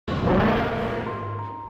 Play Godzilla 2014 Growl 5 - SoundBoardGuy
Play, download and share Godzilla 2014 growl 5 original sound button!!!!
godzilla-2014-growl-6.mp3